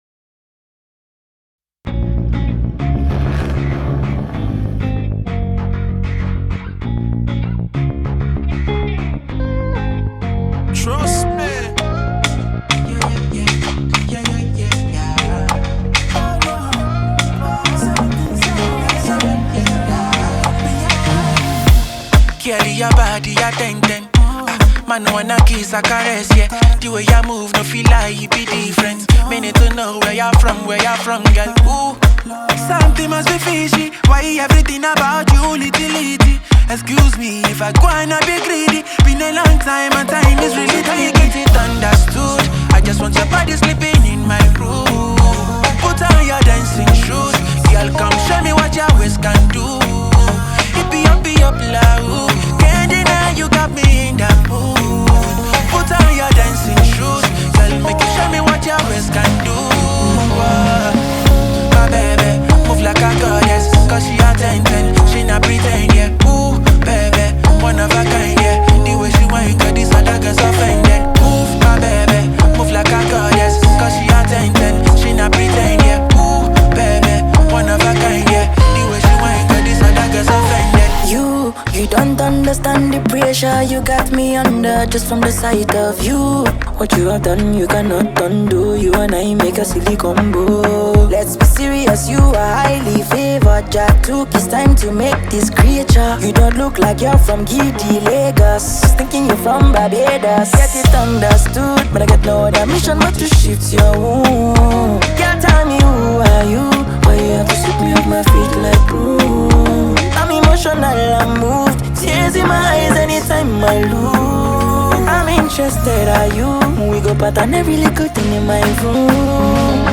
smooth vocals and the catchy hook
blends Afrobeat with contemporary sounds
With its upbeat tempo and catchy sounds